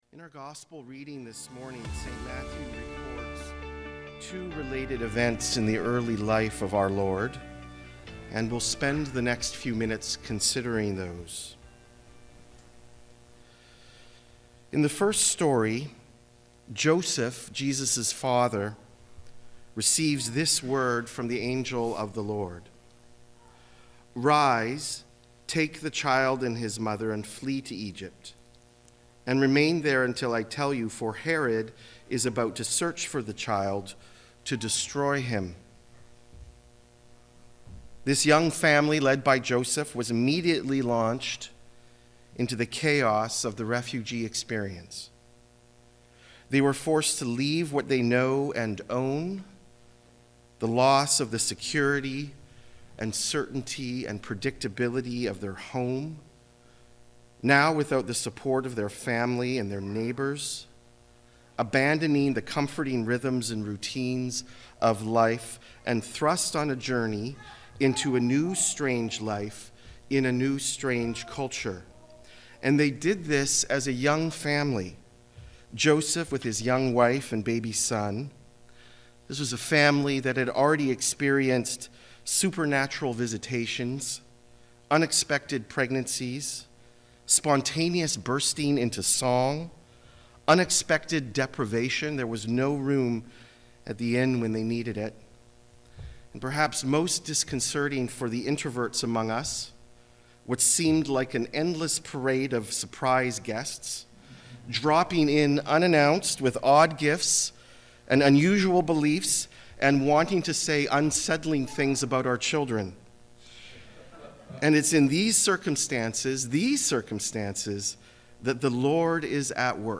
Non-Series Sermons